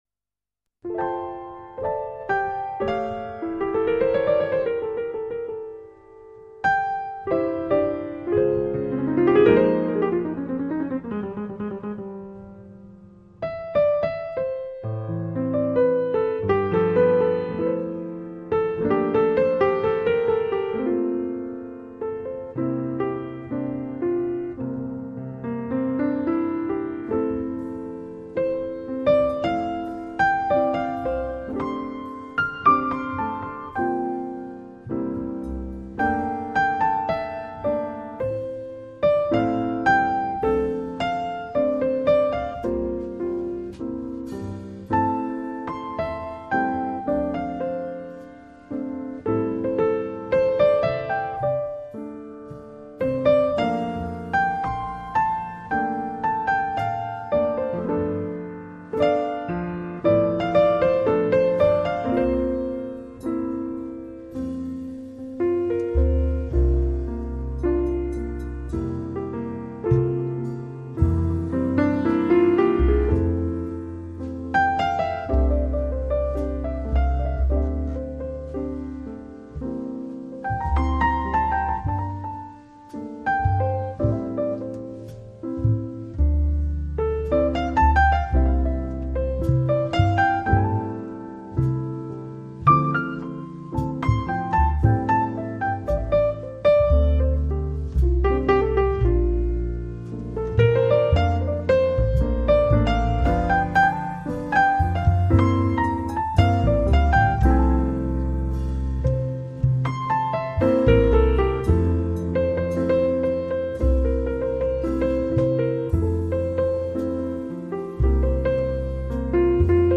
这是爵士的，是世界的，轻摇款摆的情致和自由不羁的风骨张扬着无尚的魅力。
内容是用爵士小乐团的演奏方式改编诠释中国的经典乐曲。
钢琴
贝司
吉他